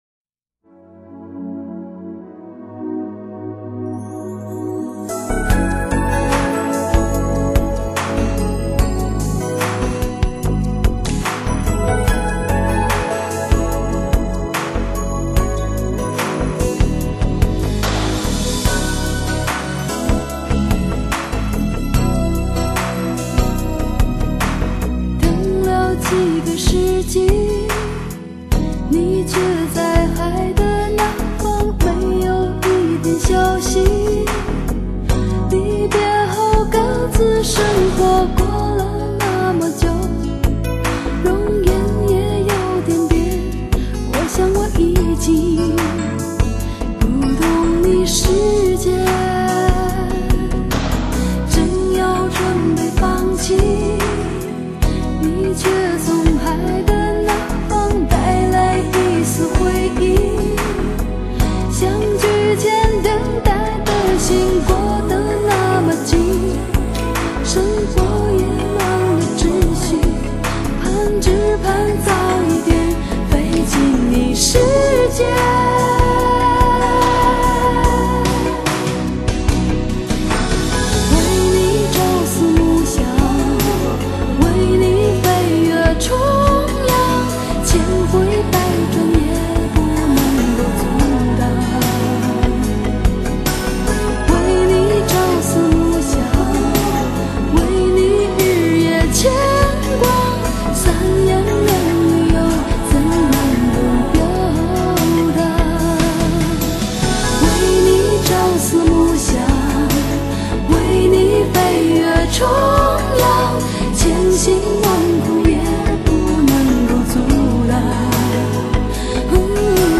拥有天籁般的嗓音